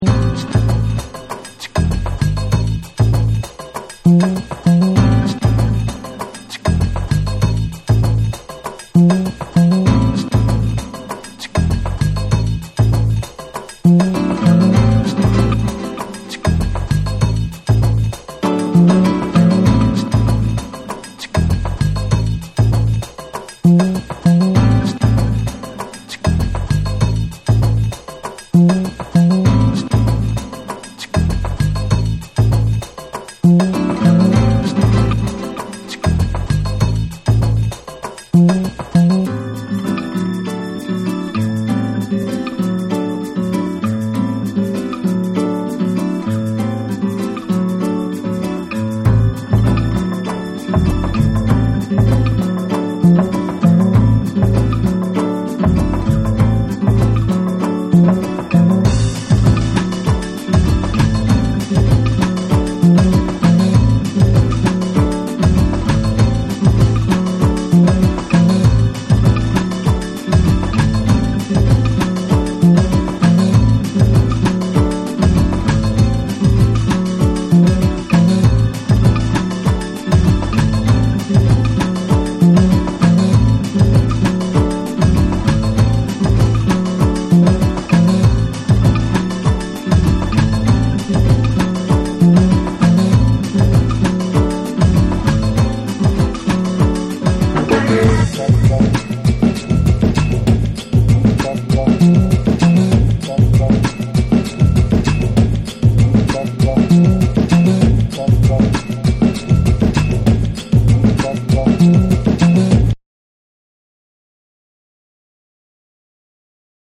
JAPANESE / ORGANIC GROOVE